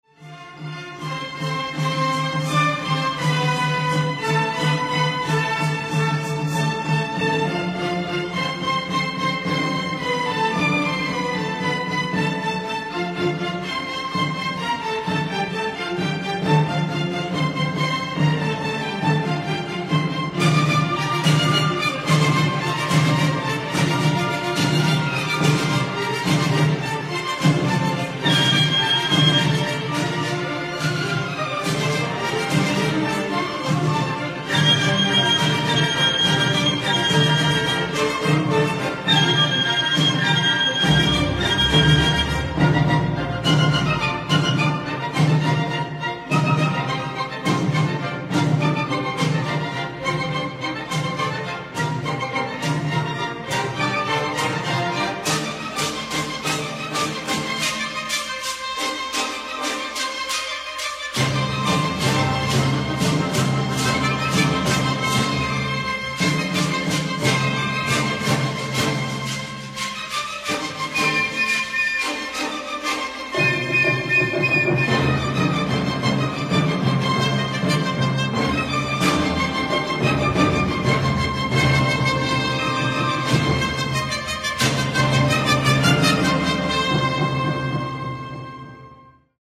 La Sinfónica de Yucatán brillará también con la interpretación de la Sinfonía No. 2 “India” de Carlos Chávez, que contiene reminiscencias de los ritmos y danzas indígenas de México, por lo que constituye una de las obras maestras de la música nacionalista.